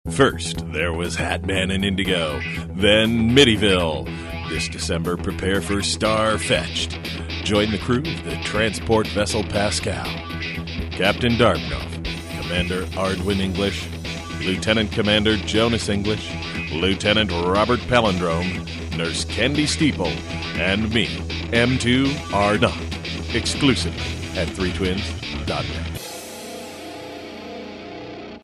Star Fetched Audio Comic Promo
Middle Aged